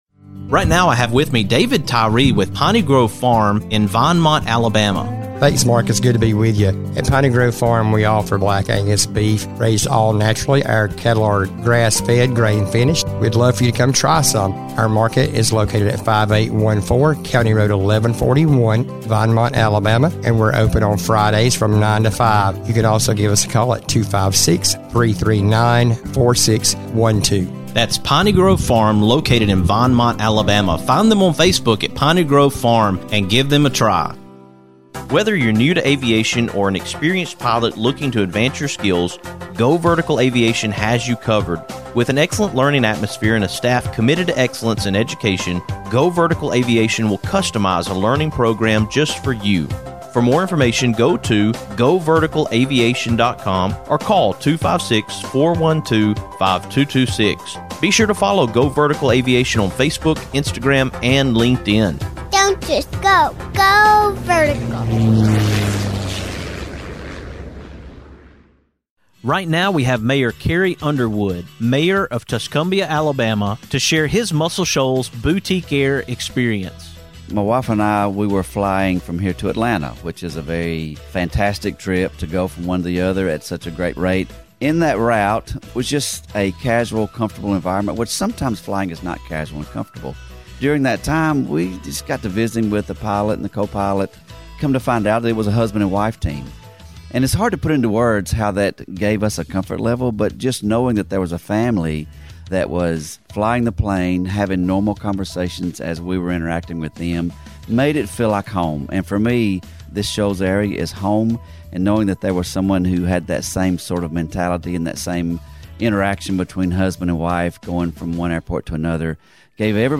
On this edition of TMWS, I am coming to you from Frierson, Louisiana, at Warrior Horse!